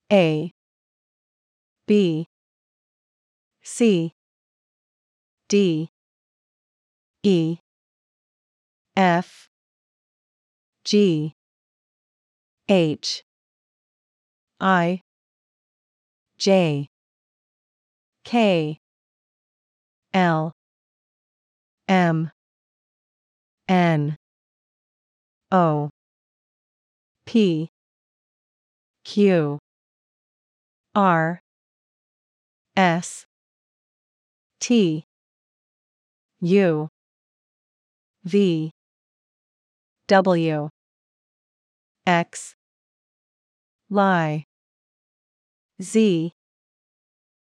In this lesson we will teach you the American pronunciation of the individual letters!
Female Speaker
Learn-English-Online-The-English-Alphabet-ABC-Pronunciation-female.mp3